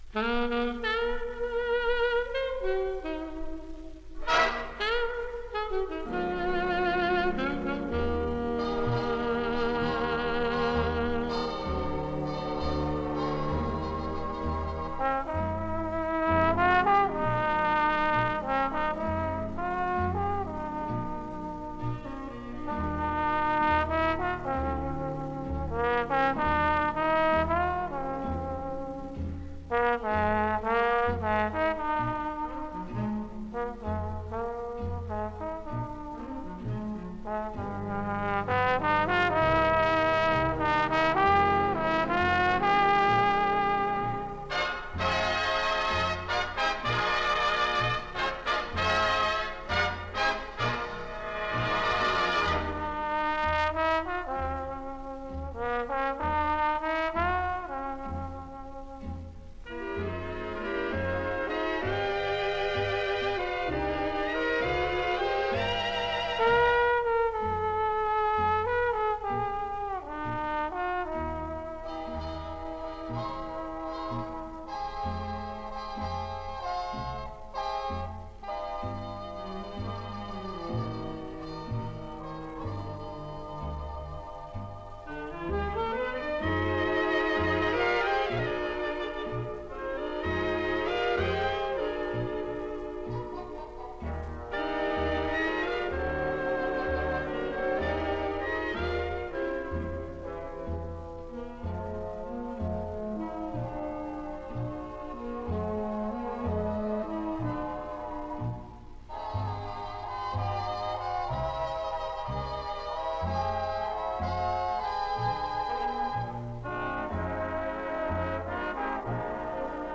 some 78 rpm sides from the late big band era